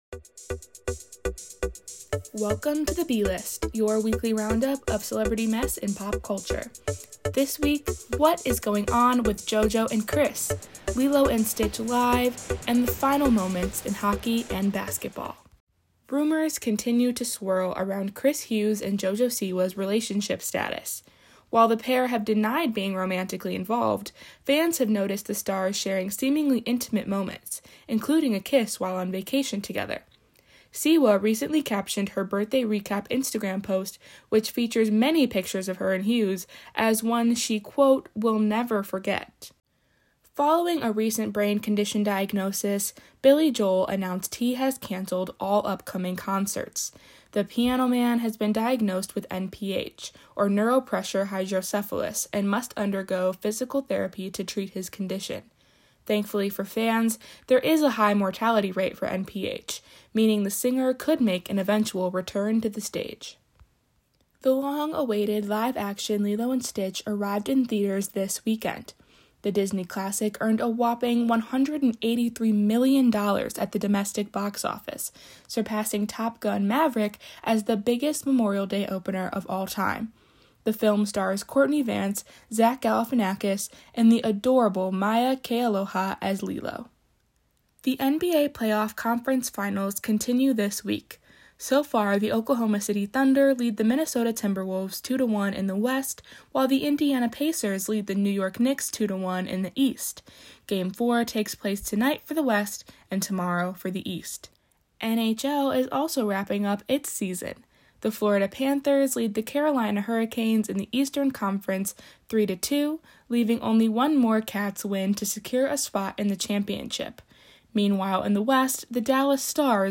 Theme music is from MixKit “What What?! Wowow!” by Michael Ramir C.